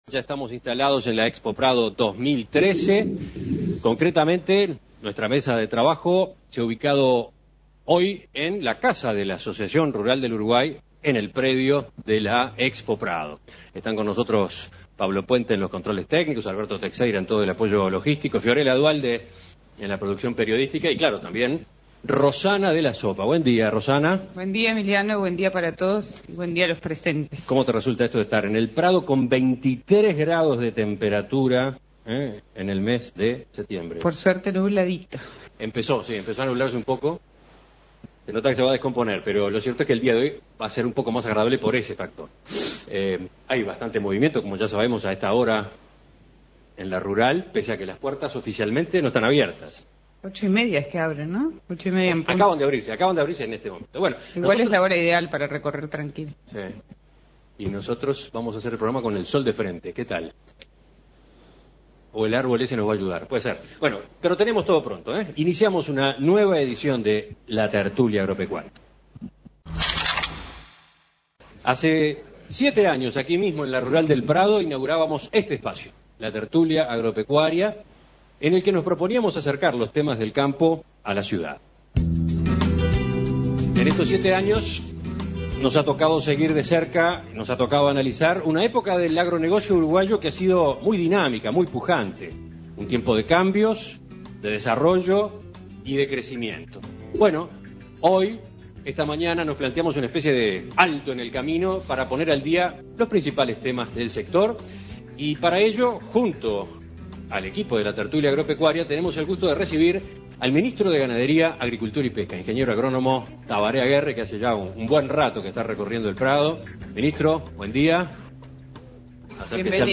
A siete años de su primera emisión, La Tertulia Agropecuaria se instaló en la Expo Prado para recibir al ministro de Ganadería, Agricultura y Pesca, Tabaré Aguerre, para poner al día algunos de los temas más importantes del agro uruguayo.